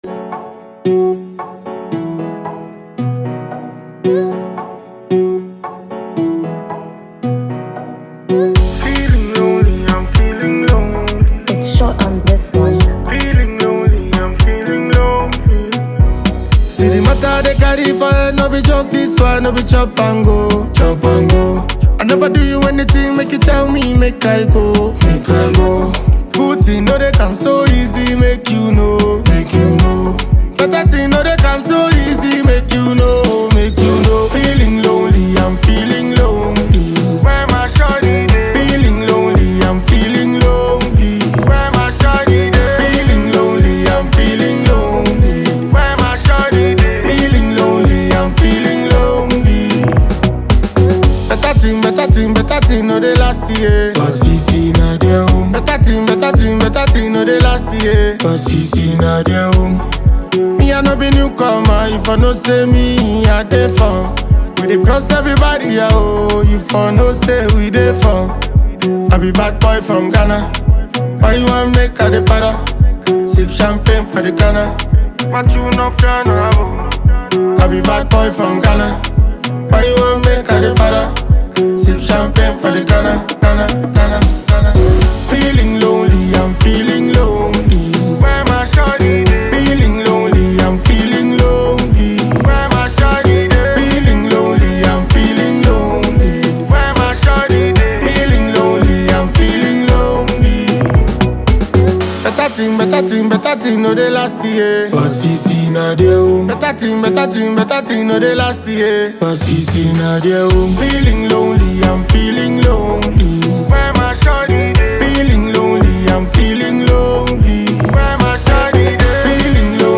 Sensational Ghanaian reggae-dancehall musician